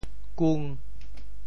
潮州府城POJ kûng 国际音标 [kun]